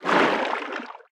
Sfx_creature_snowstalker_swim_01.ogg